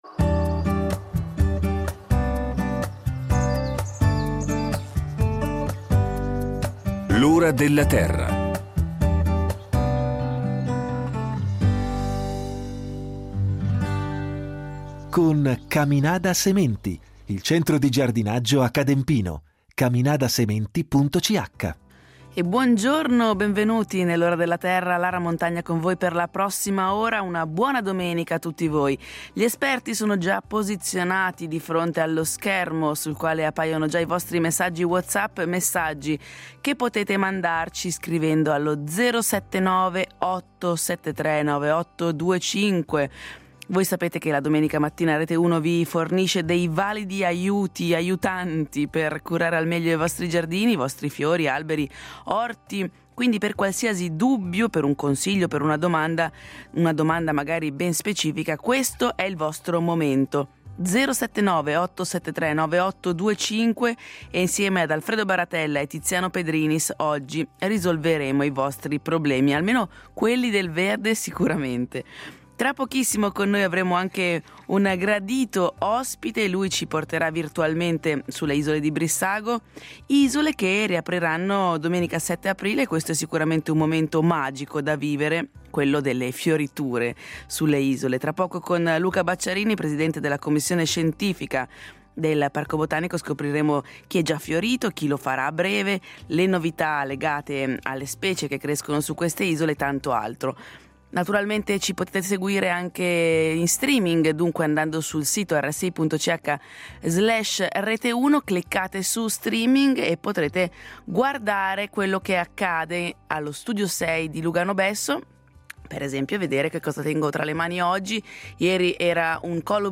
In studio gli esperti de L’Ora della Terra.